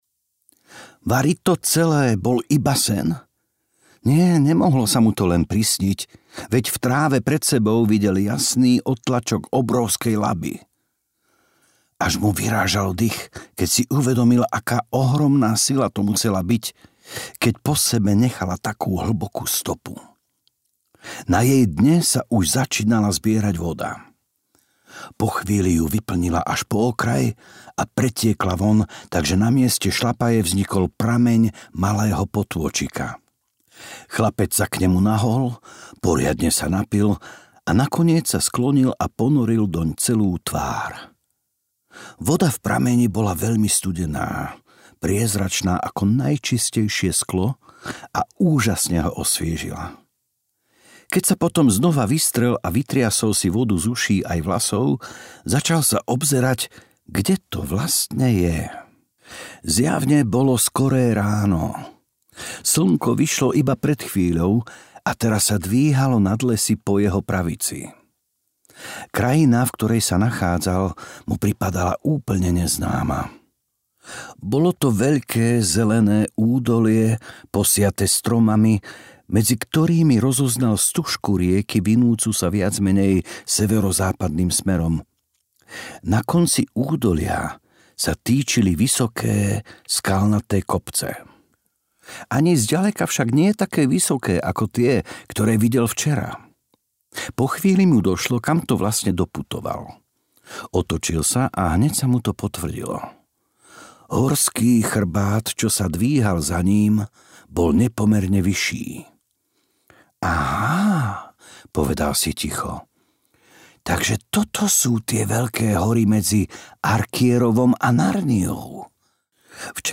Kôň a jeho chlapec audiokniha
Ukázka z knihy